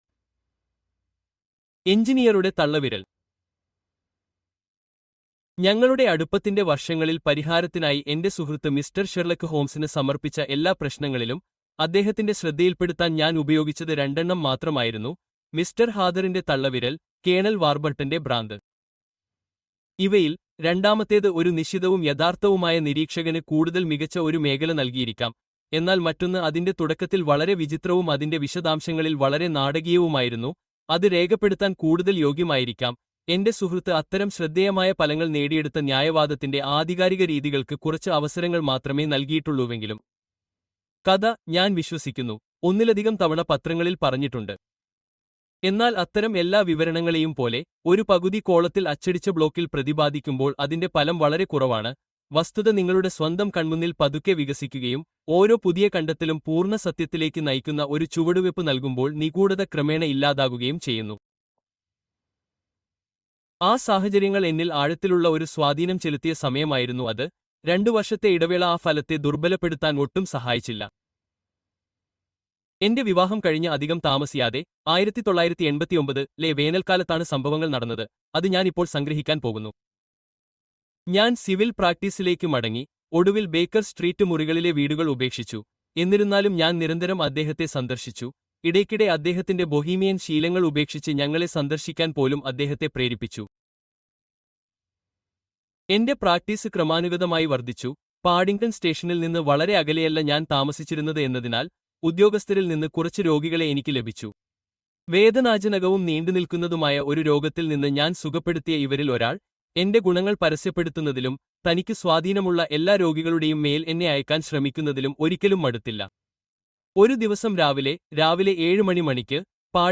The Disappearance of Lady Frances Carfax: Mystery Unveiled (Audiobook)